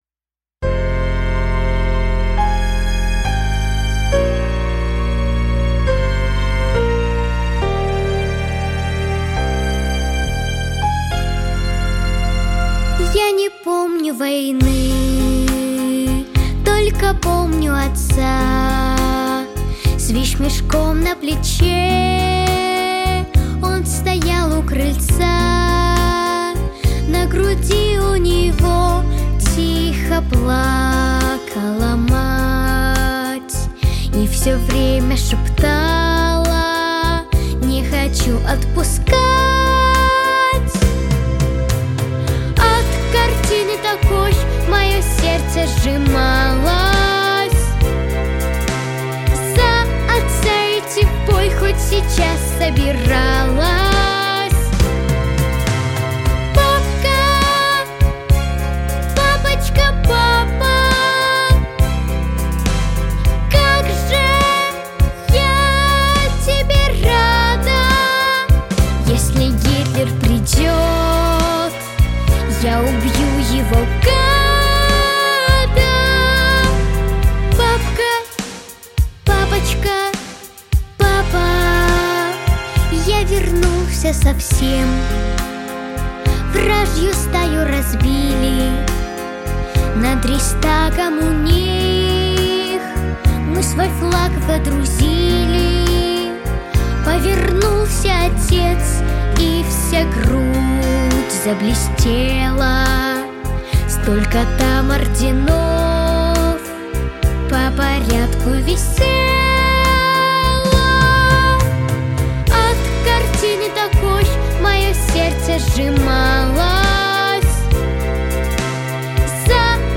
• Качество: Хорошее
• Жанр: Детские песни
🎶 Детские песни / О близких людях / Песни про папу